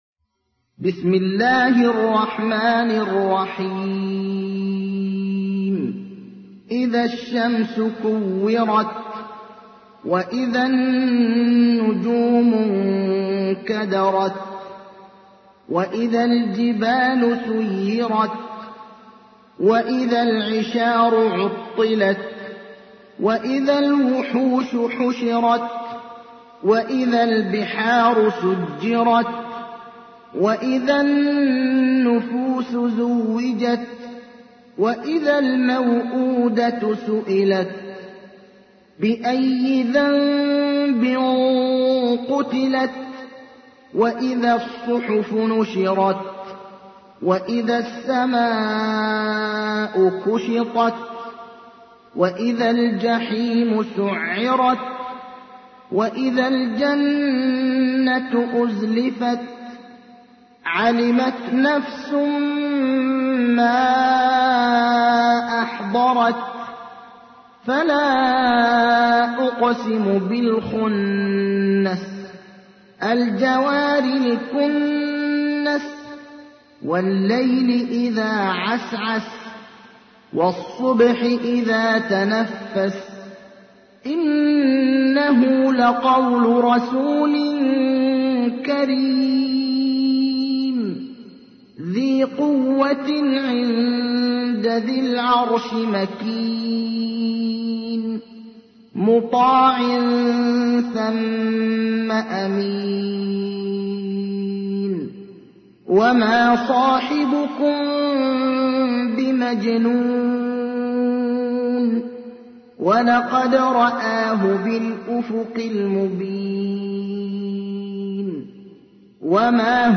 تحميل : 81. سورة التكوير / القارئ ابراهيم الأخضر / القرآن الكريم / موقع يا حسين